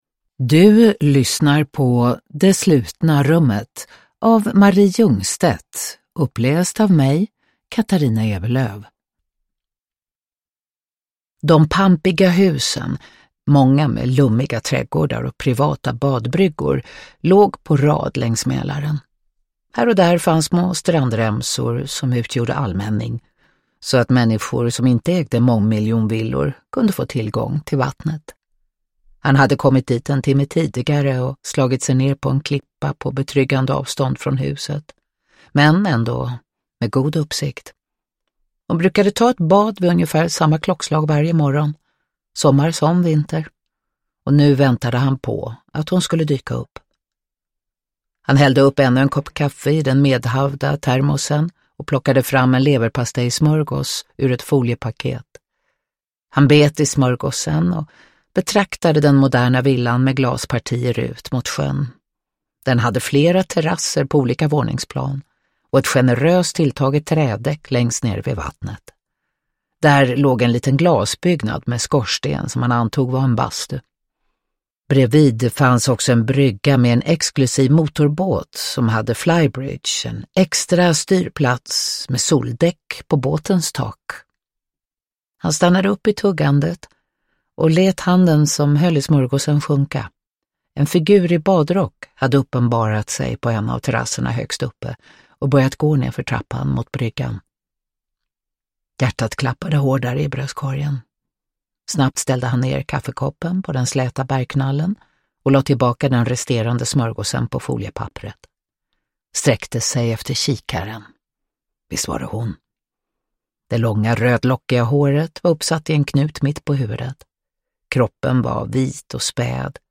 Det slutna rummet – Ljudbok – Laddas ner
Uppläsare: Katarina Ewerlöf